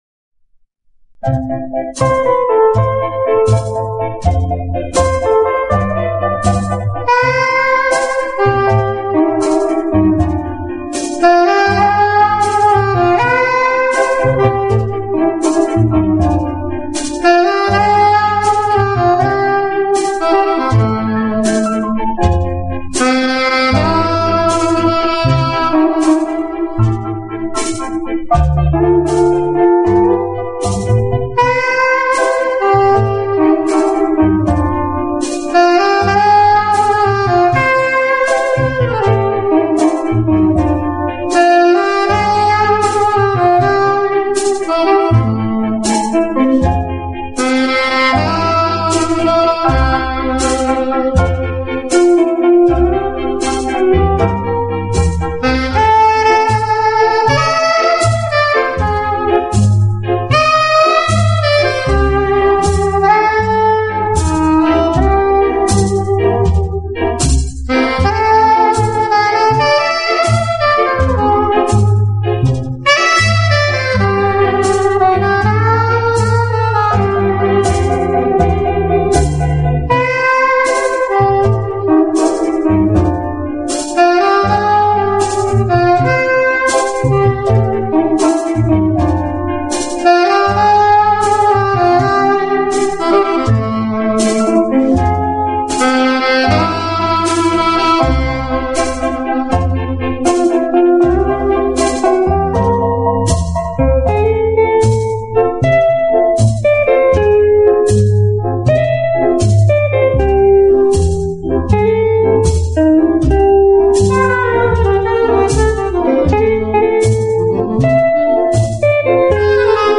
【爵士萨克斯】
用萨克管演奏情调爵士乐，上世纪六十年代开始很走红，到上个世纪七十年